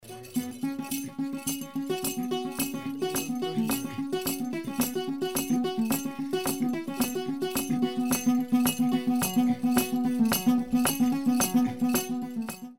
Nyatiti - Litungu - Afrisson
Sous le nom nyatiti, cette harpe appartient à l'ethnie luo du Kenya, possède 6 cordes et le nom nyatiti est un nom féminin, le préfixe «~nya~» signifiant «~la fille de~».
nyatiti.mp3